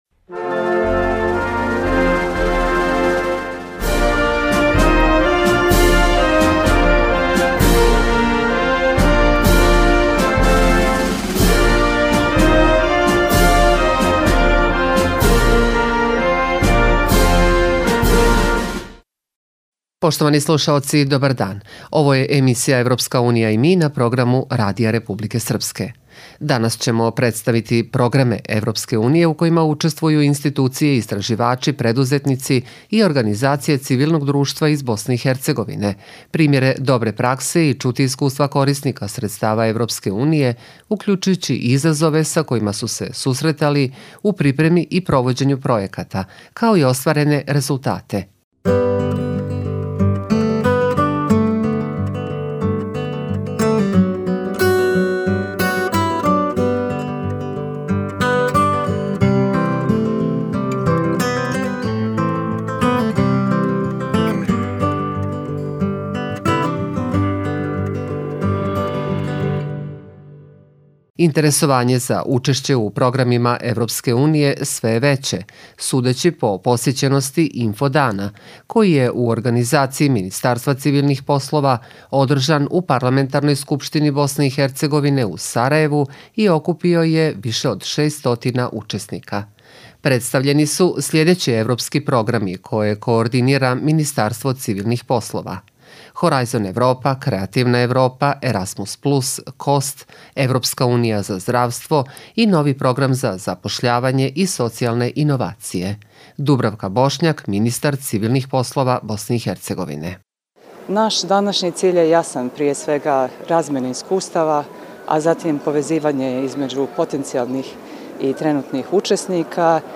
EU i mi: Radio emisija o programima Evropske unije